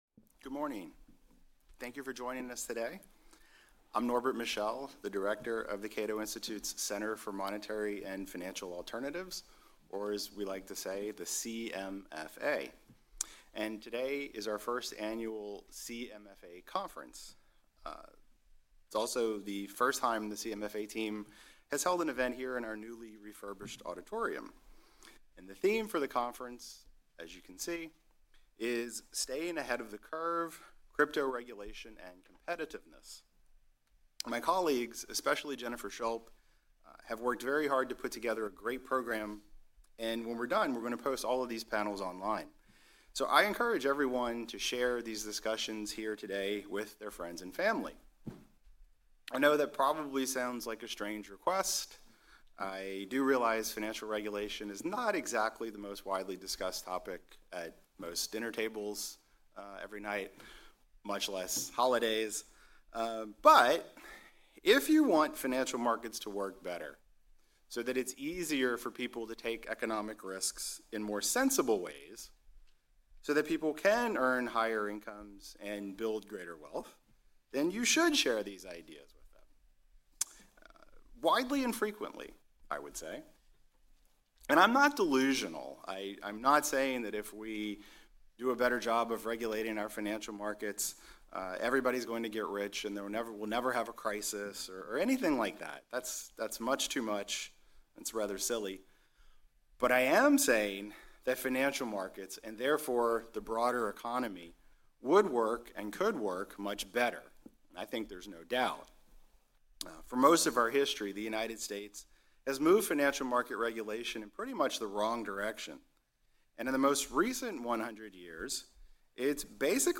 Staying Ahead of the Curve: Crypto Regulation and Competitiveness: Opening Remarks and Fireside Chat with Senator Bill Hagerty (R‑TN)